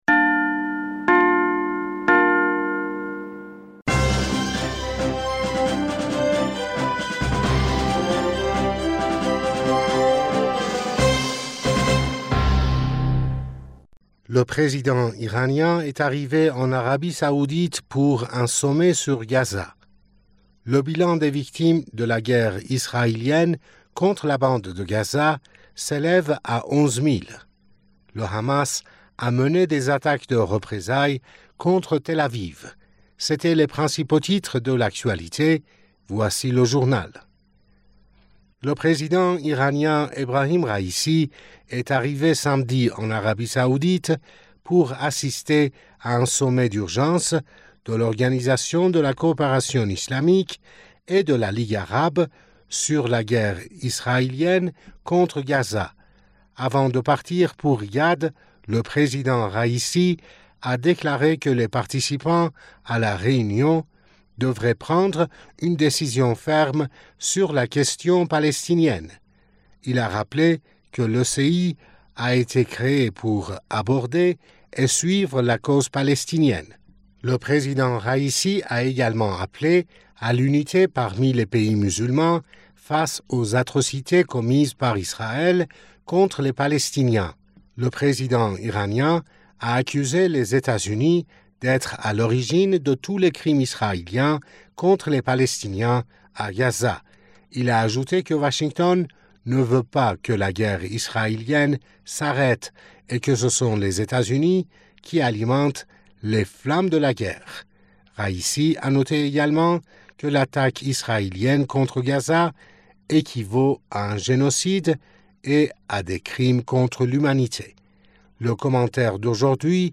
Bulletin d'information du 11 Novembre 2023